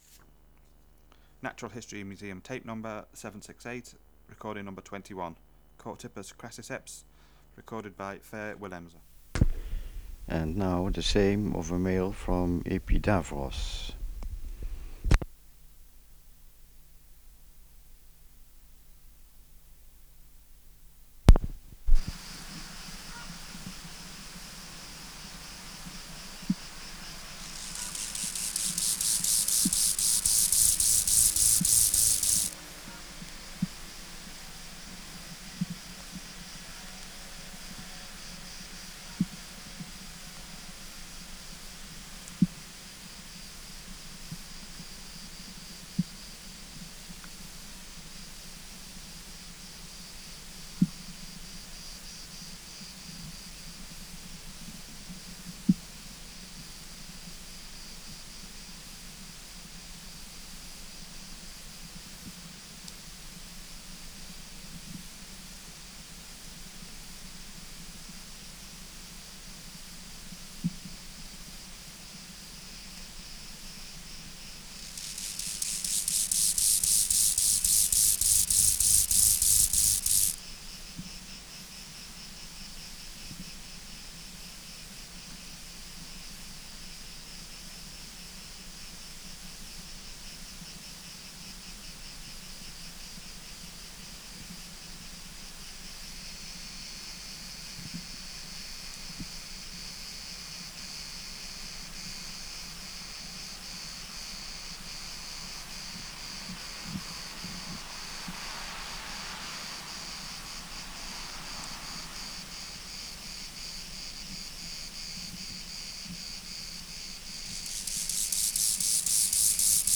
Project: Natural History Museum Sound Archive Species: Chorthippus (Glyptobothrus) crassiceps